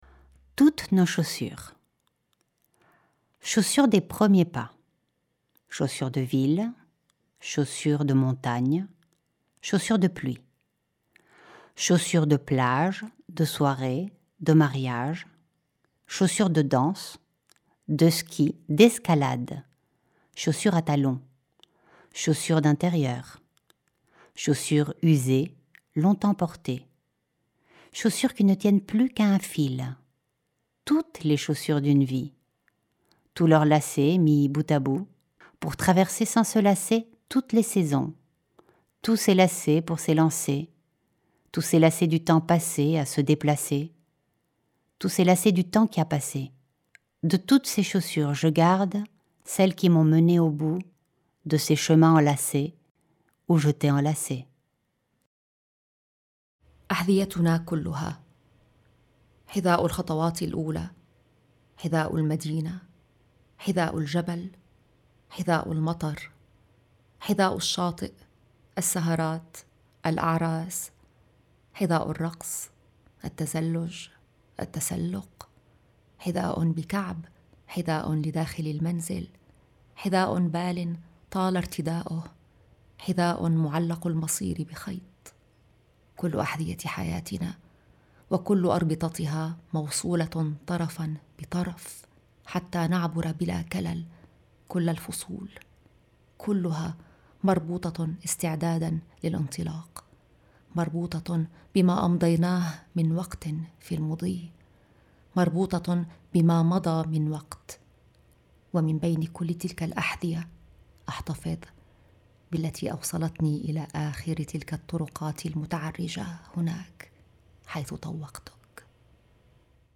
création musicale
lecture en français
lecture en arabe